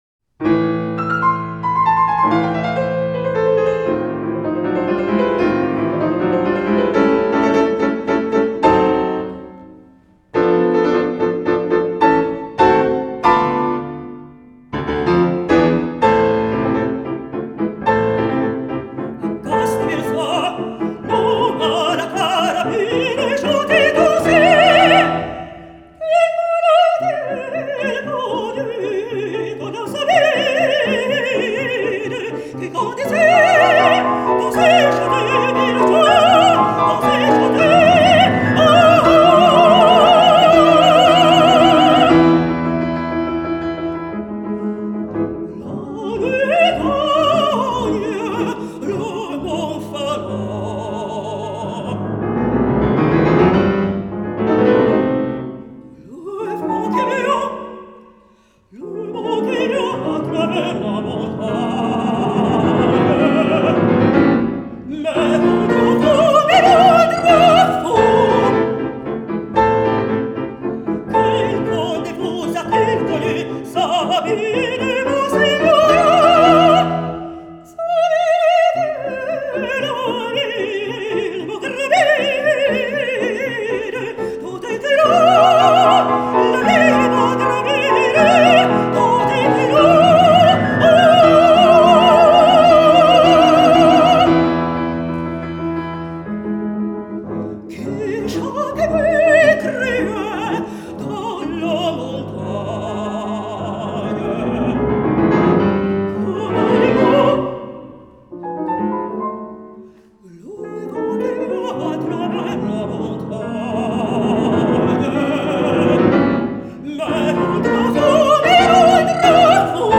mezzosoprano
pianoforte
Luogo esecuzioneParma - Auditorium del Carmine
GenereMusica Classica / Cameristica